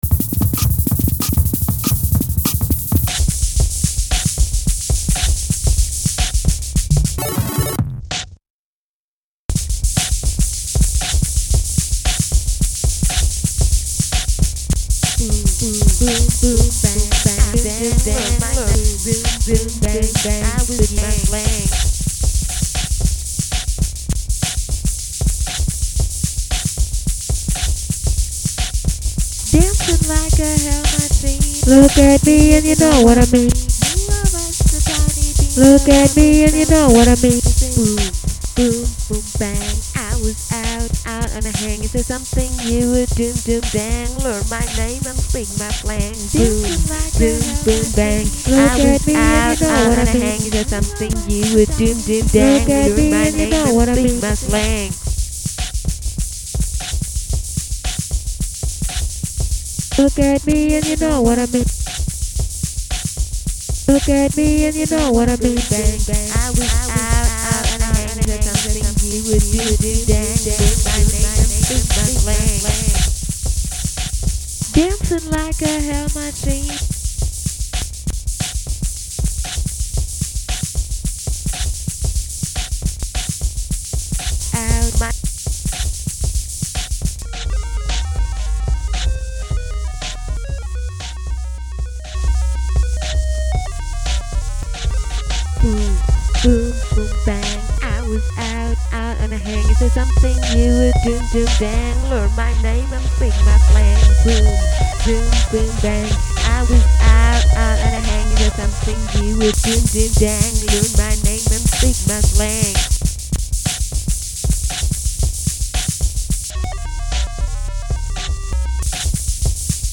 Electropunk